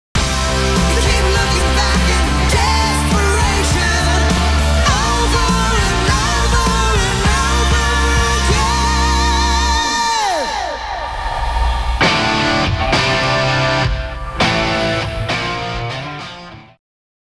Now background noise has nowhere to hide!